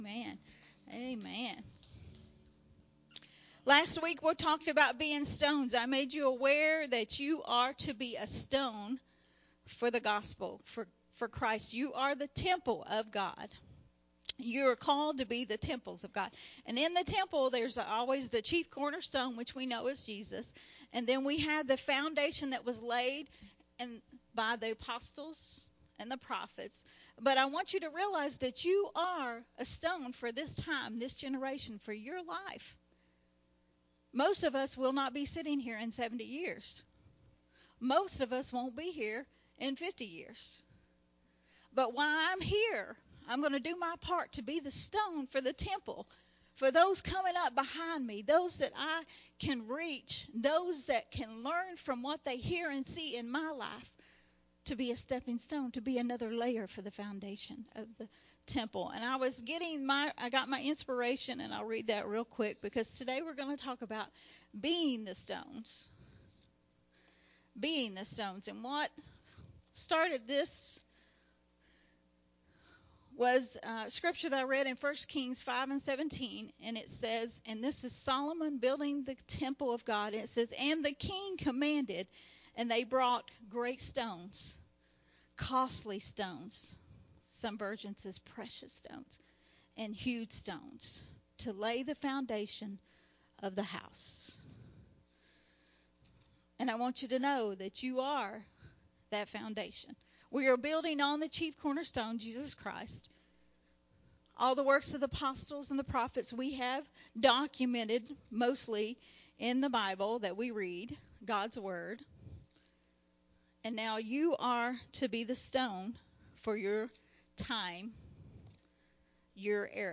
a Sunday Morning Refreshing teaching
recorded at Unity Worship Center on July 24th